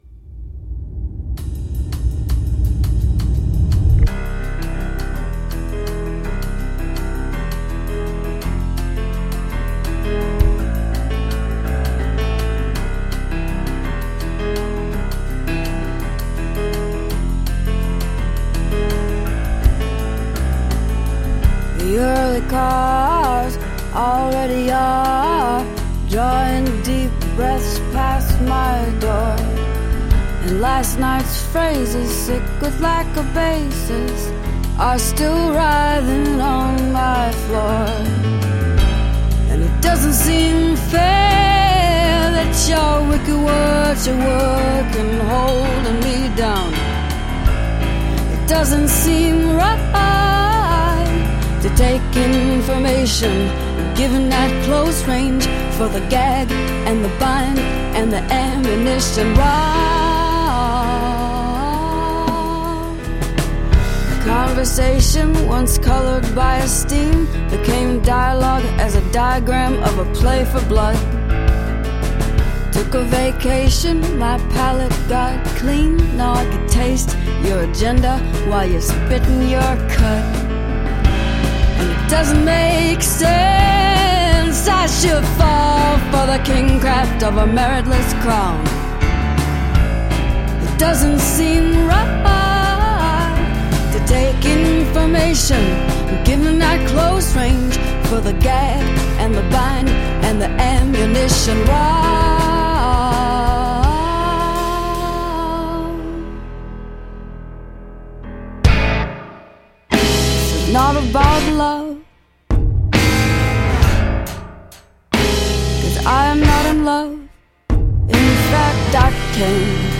fittingly off-kilter performance